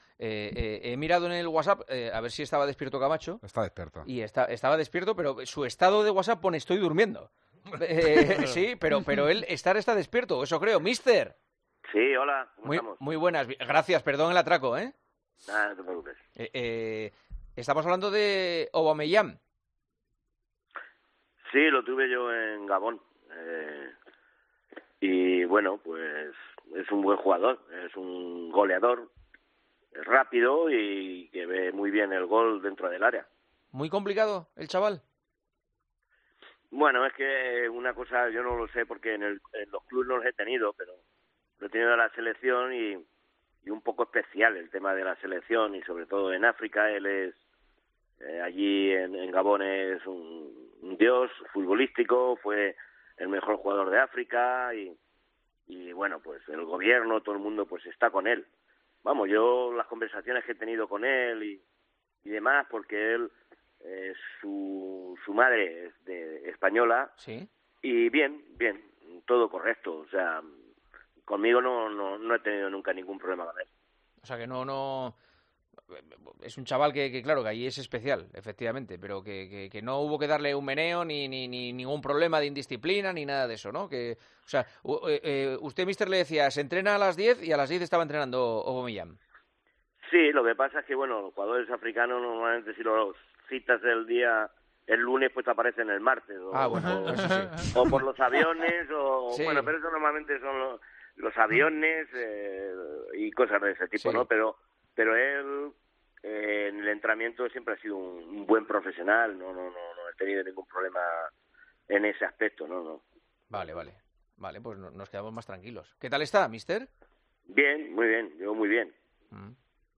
Camacho, ex seleccionador de Gabón, habla de Aubameyang en El Partidazo de COPE